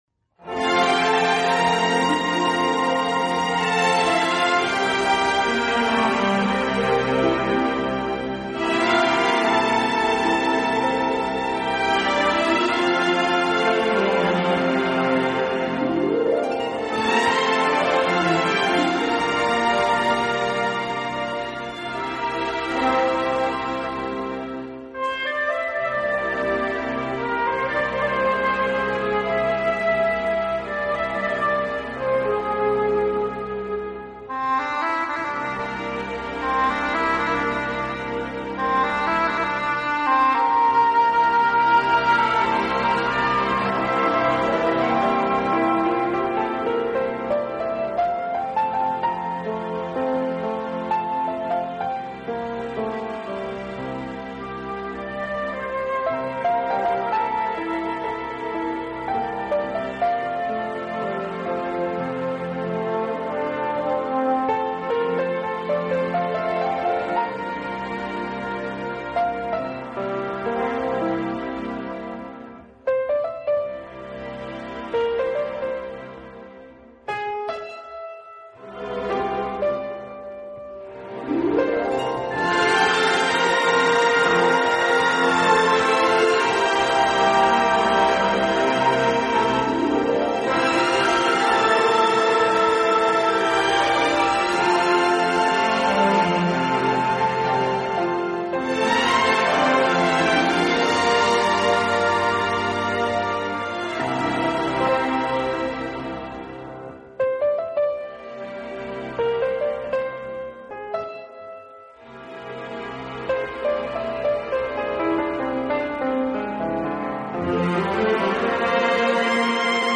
乐队具有完整的管乐器组和弦乐器
组，非常擅长将原曲中最优美的部分表现出来。